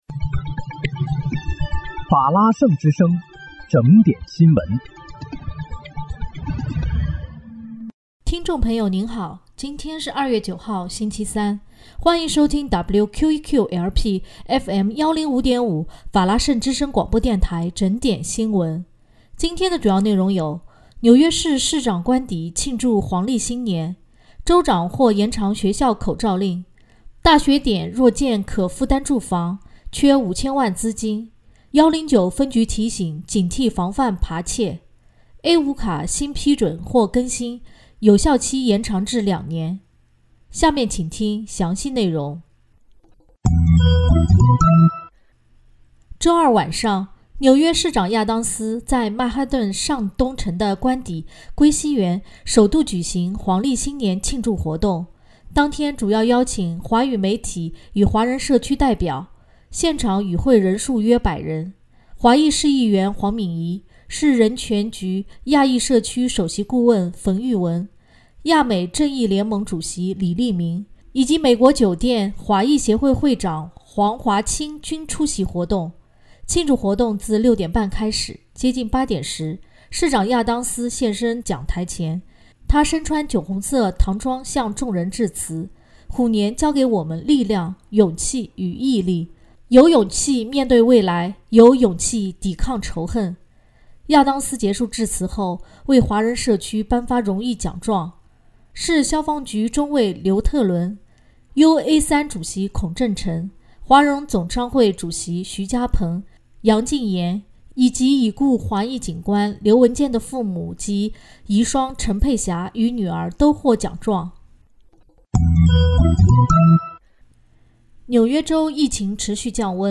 2月9日（星期三）纽约整点新闻